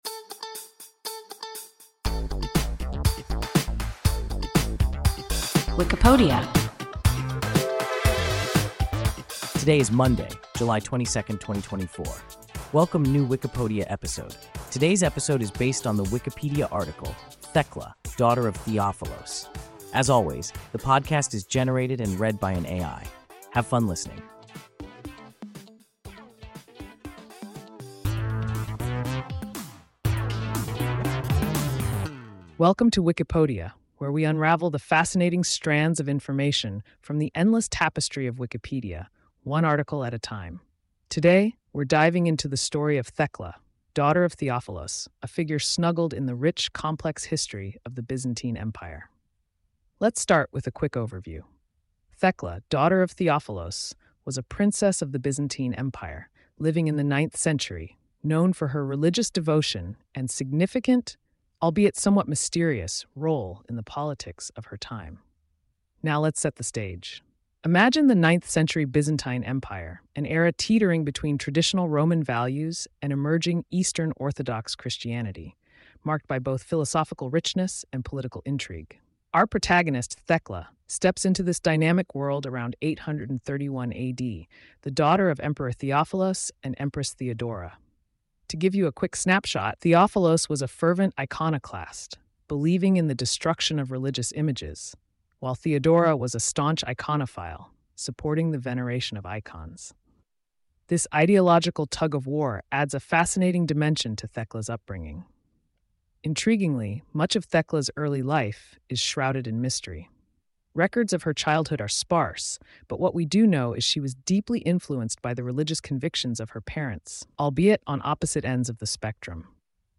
Thekla (daughter of Theophilos) – WIKIPODIA – ein KI Podcast